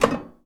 metal_tin_impacts_soft_01.wav